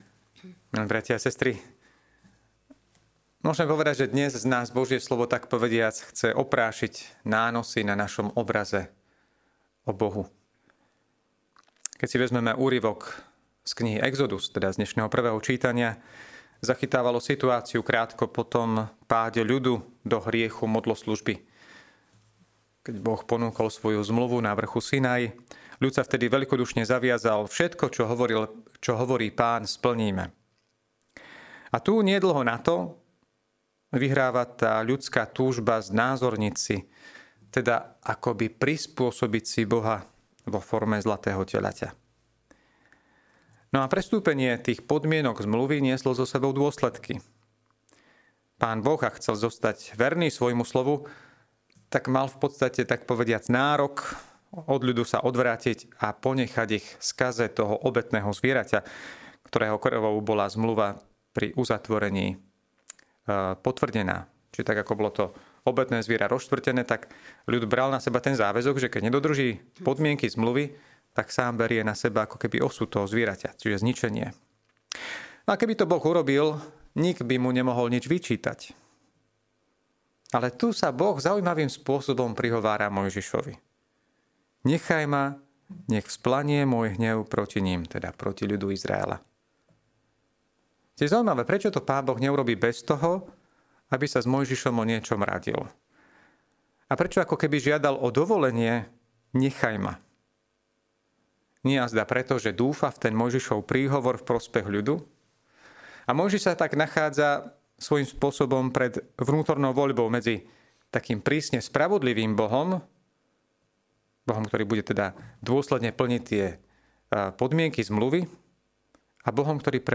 Podcast Kázne
Štvrtok po 4. pôstnej nedeli